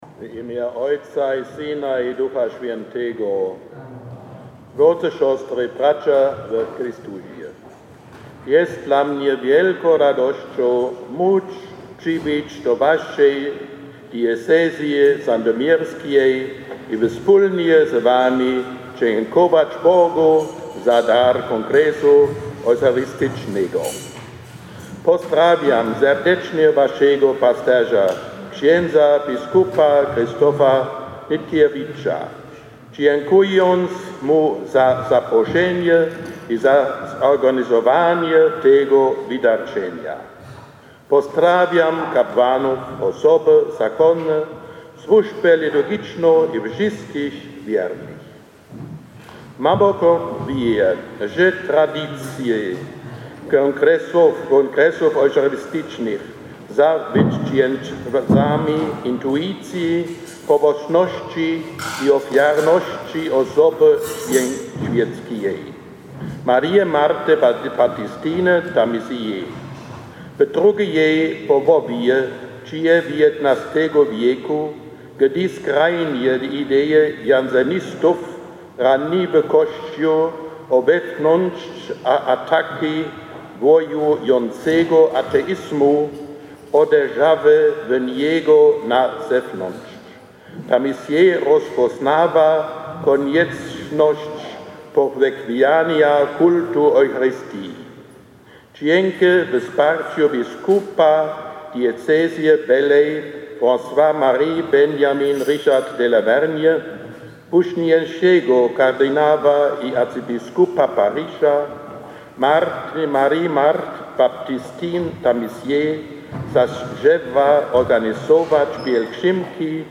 Posłuchaj homilii kardynała Gerharda Mullera:
Natomiast mszy świętej w katedrze przewodniczył kardynał Gerhard Müller, który wygłosił homilię w języku polskim.
homilia.mp3